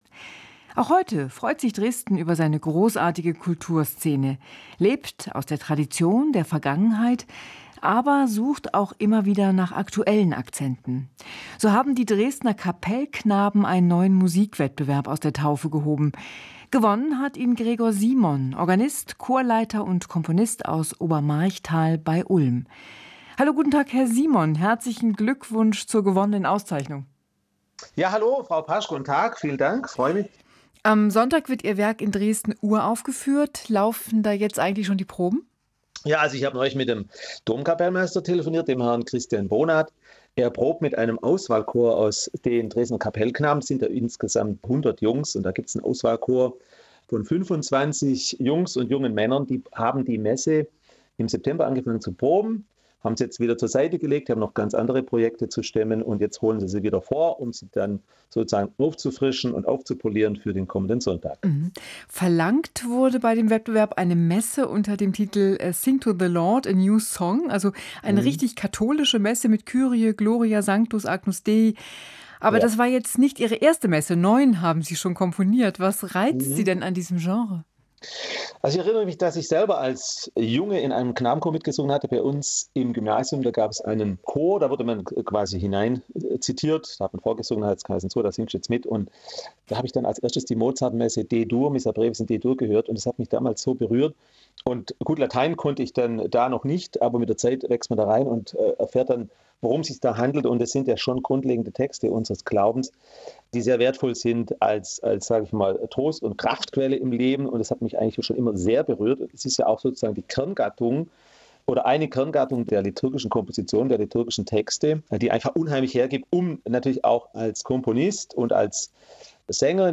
Musikgespräch